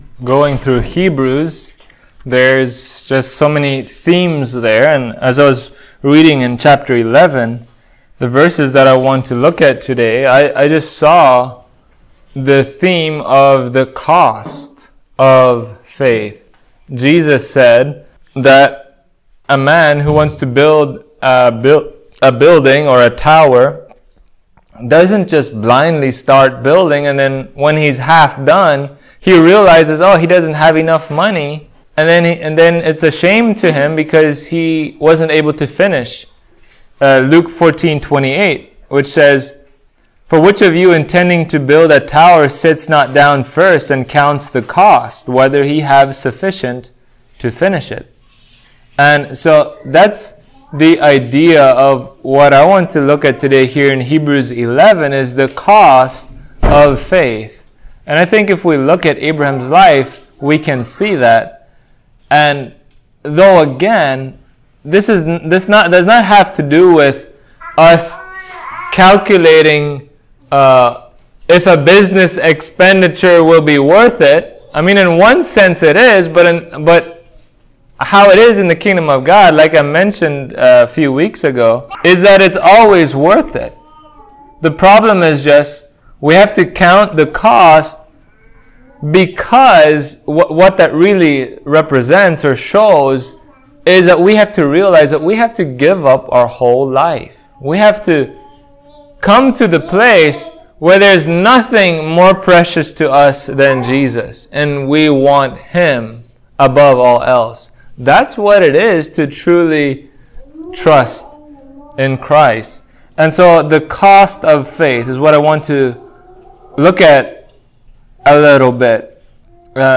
Hebrews 11:17-19 Service Type: Sunday Morning True faith does not drift aimlessly in life without obeying God.